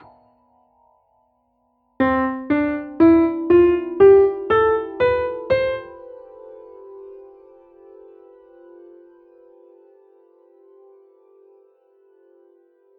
Ou avec Ivory (pédale harmonique émulée avec la pédale sosteneto... on entends la plupart des touches au début) - c'est tout de même moins réussi (mais ce type de résonance a été développé pour gérer quelques notes au sein d'un accord... là, c'est un peu perverti).
harmonic_ivory.mp3